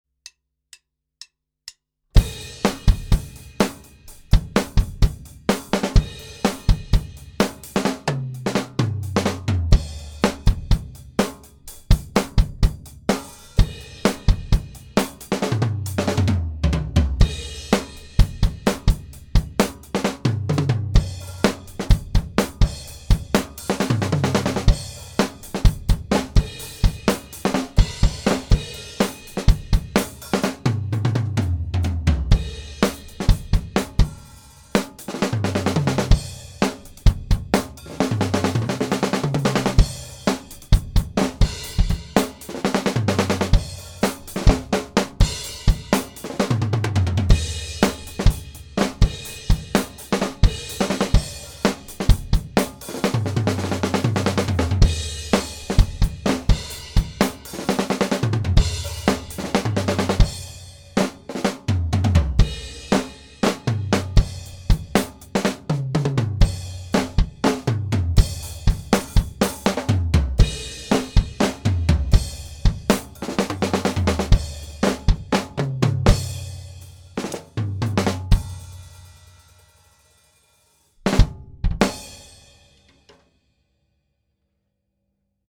la caisse claire manque de claquant, est ce que le batteur tapait la caisse claire le centre de la peau et le cercle en meme temps ?
la grosse caisse on dirai que la peau de frappe est detendue non ?je dirai qu' elle a du claquant mais il lui manque un peu de profondeur!
les toms sont dans lensemble bien accordés !
les cymbales paraissent lointaine mais je sais pas si c' est ta prise ou le reglage du volume au mixage.
il y a une bonne homogénéité des cymbales et de l' ensemble!
Citation : les pistes n'ont aucun traitement (pas d'equ, pas de traitement dynamique, pas de reverbe)
les pistes ont juste été pannées et les niveaux ajustés pour faire ressortir un peu les futs (l'afien en question voulait entendre le son d'un kit MMX, donc j'ai mis la GC et les toms en avant)
- La peau de grosse caisse est effectivement très détendue.
- Je tappais la caisse claire environ au centre, c'est vrai, pas en rimshot (le batteur c'était moi :mrg: ) La caisse claire est une free floating system en cuivre avec une vieille peau bien abimée.
- le 2nd tom bass (16" ) a une peau moins recente que les 3 autres, et effectivement il a un son moins ouvert que les 3 autres.
- et comme auto-cité plus haut, les futs du kit MMX (grosse caisse / toms) ont été mis en avant au mix (et forcément les cymbales, CC, sont un peu en arriere)
MMX - take 1 - raw.MP3